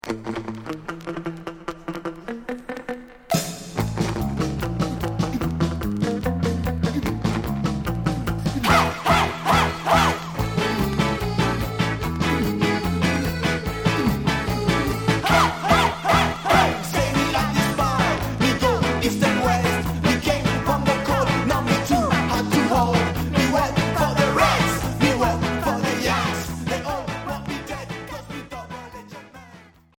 Alternatif